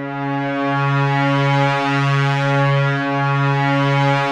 P.5 C#4 7.wav